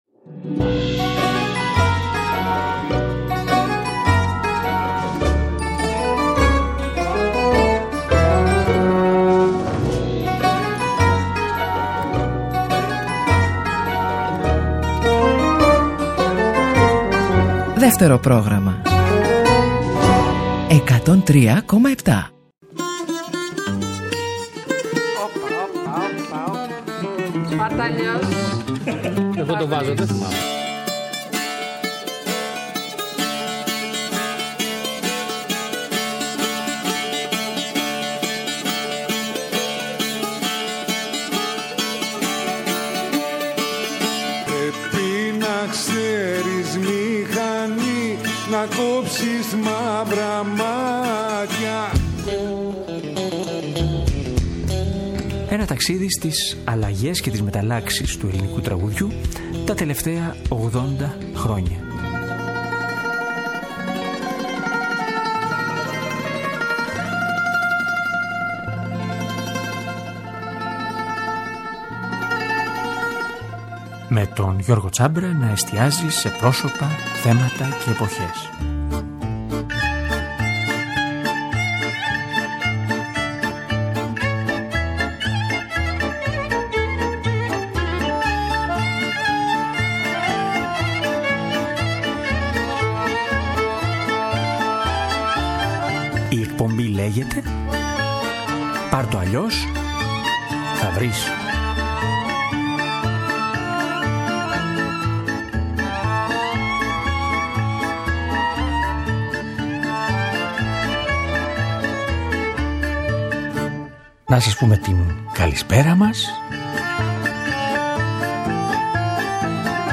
Στην εκπομπή μιλάμε για όλα αυτά και ακούμε αποσπάσματα από ηχογραφήσεις με τον Σπύρο Σακκά αλλά και από άλλες φωνές που μπλέκονται στις μνήμες του.